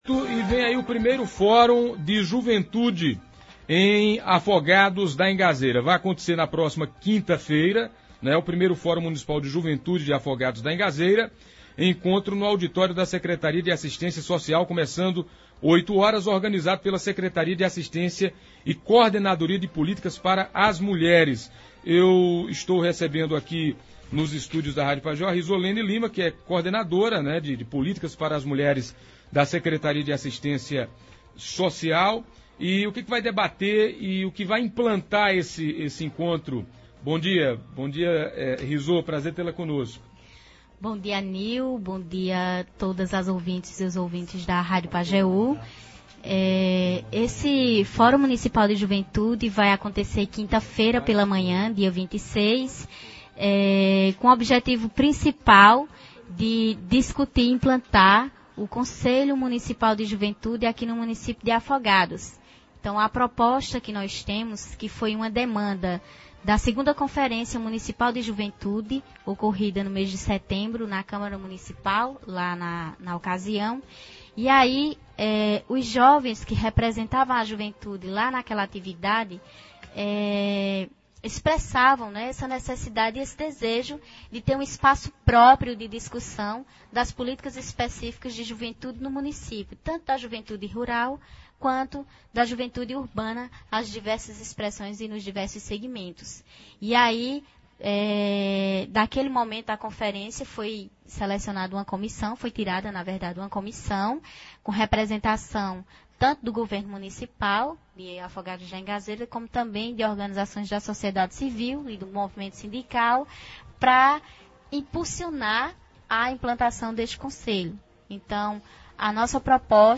Nos estúdios da Pajeú